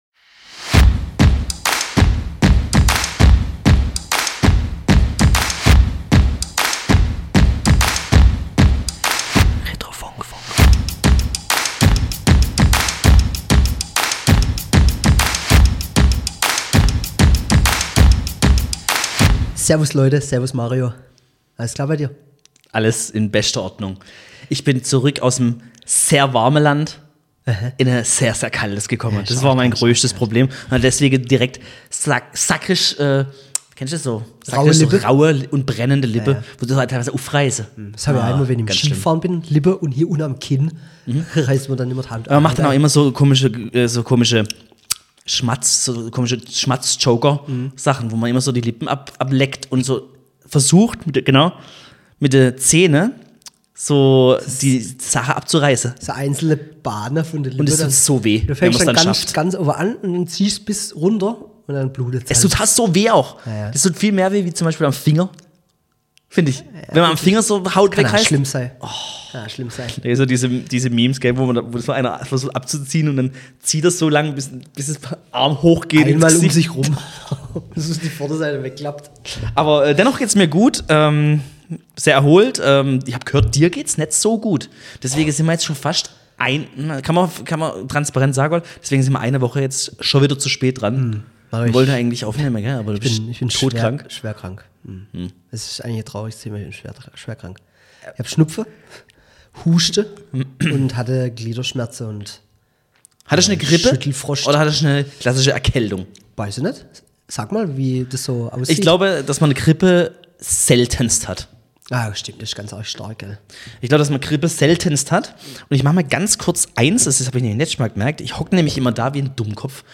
Bitzebatzevolle Folge, technisch hochwertig ausgearbeitet und sogar mit einem Gast.